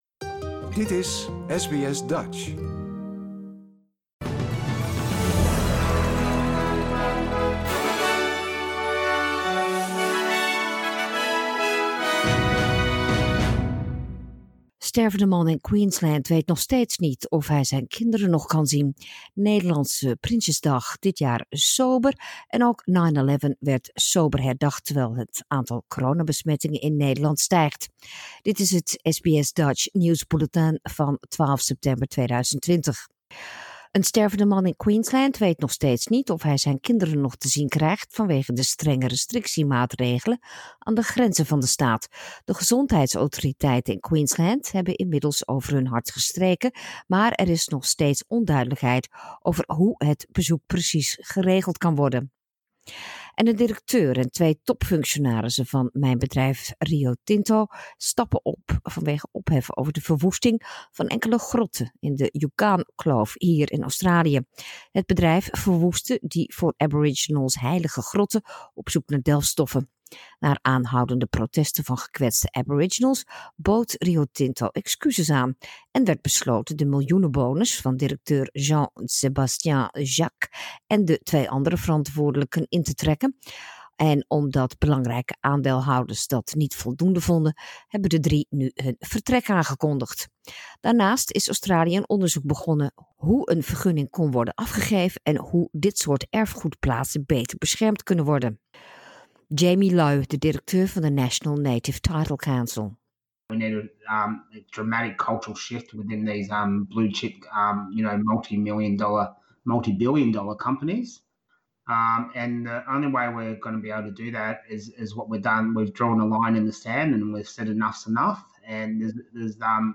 Nederlands/Australisch SBS Dutch nieuwsbulletin zaterdag 12 september 2020
dutch_1209_news.mp3